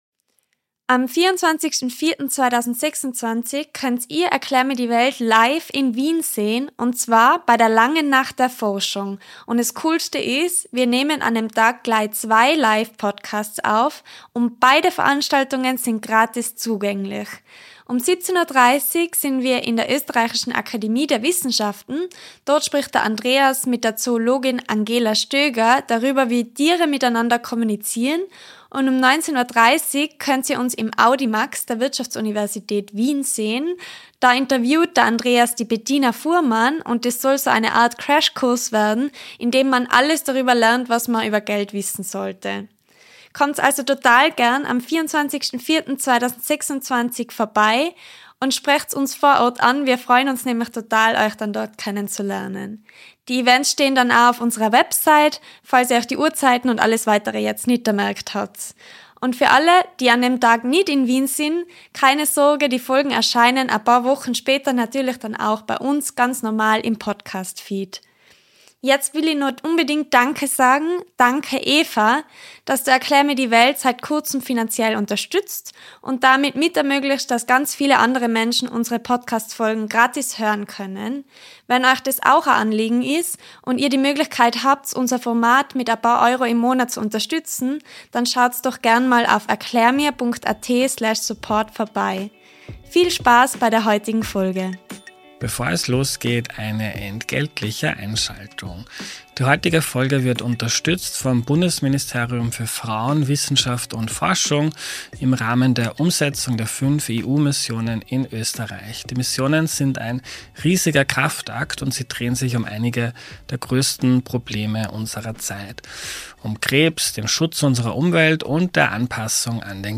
Ein faszinierendes Gespräch über Fische, Algen, den Menschen und die Natur, und wir unser Verhältnis wieder besser hinbekommen.